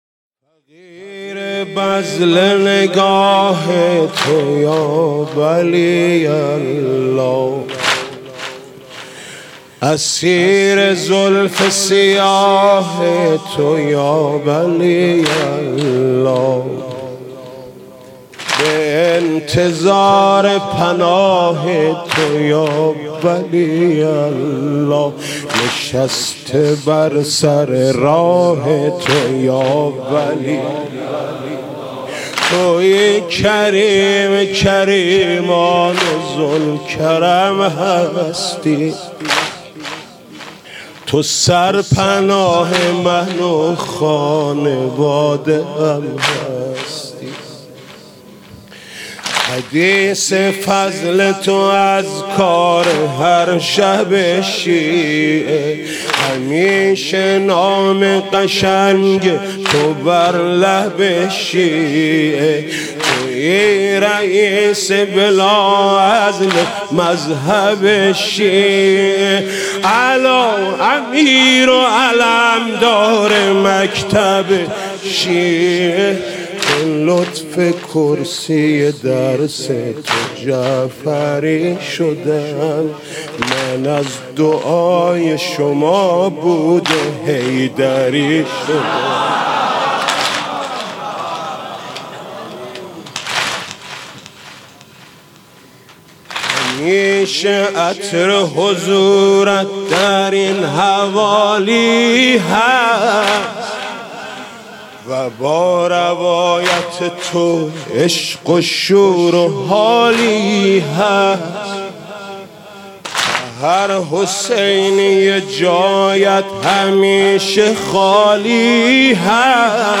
«شهادت امام صادق 1395» واحد: فقیر بذل نگاه تو یا ولی الله